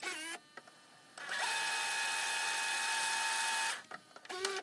Descarga de Sonidos mp3 Gratis: adelantar casette.
descargar sonido mp3 adelantar casette
fast-forward.mp3